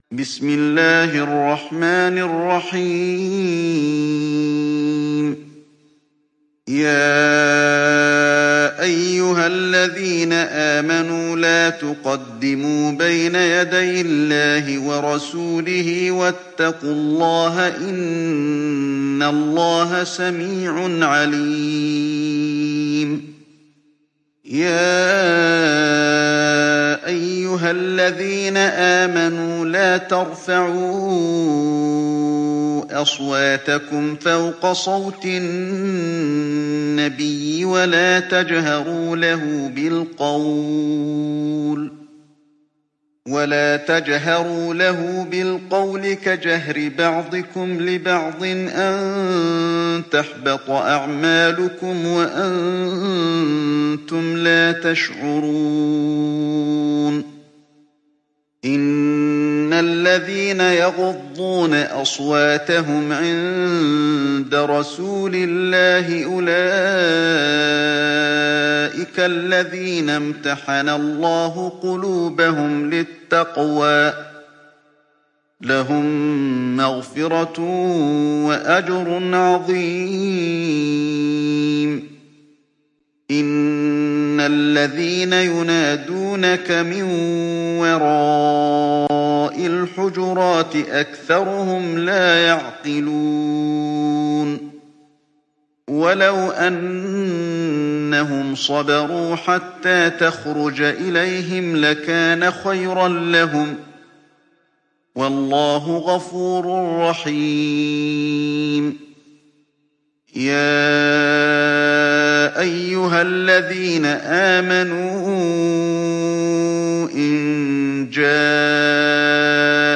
تحميل سورة الحجرات mp3 بصوت علي الحذيفي برواية حفص عن عاصم, تحميل استماع القرآن الكريم على الجوال mp3 كاملا بروابط مباشرة وسريعة